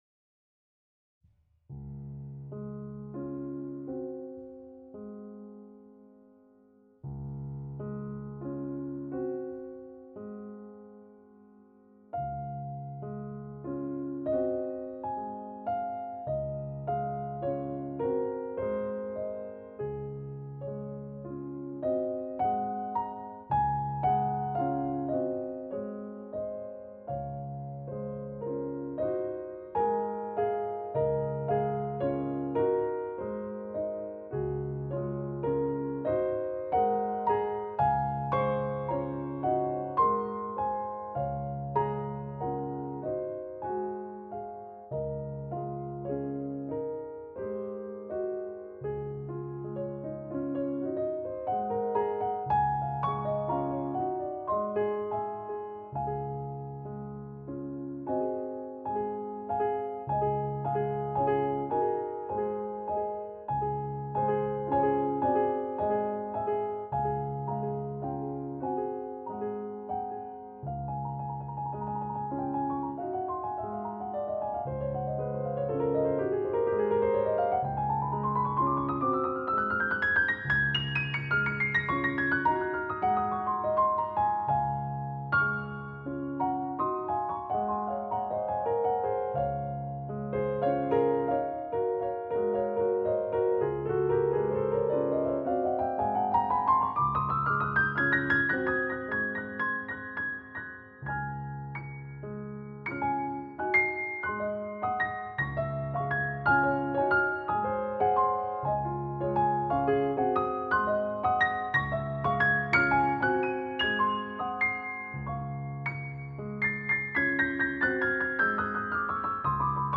８分の６拍子で単調に奏続ける安定の伴奏。
対比するように絶妙に表情を変える旋律の音運び。
右手の繊細で美しい高音に酔いしれているうちに、瞬く間に弾ききってしまいます。